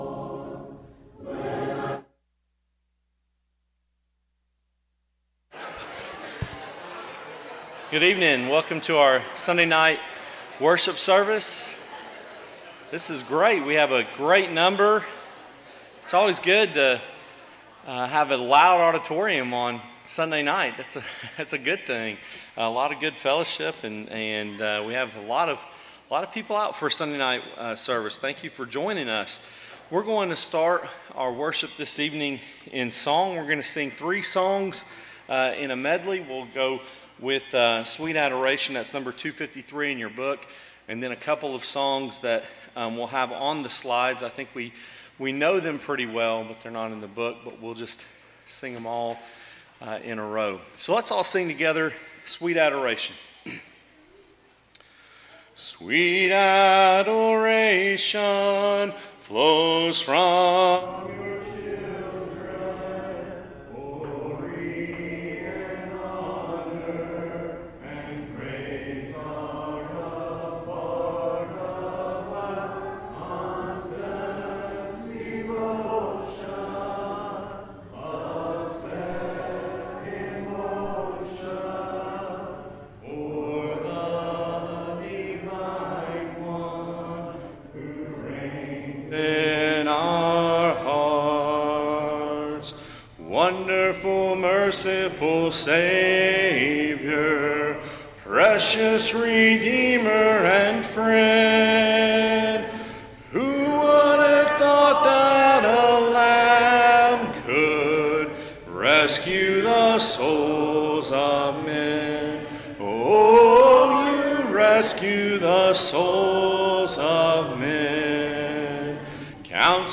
James 3:17-18, English Standard Version Series: Sunday PM Service